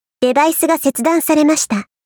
ShiroTsubaki_B_Hardware Remove.mp3